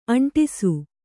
♪ aṇṭisu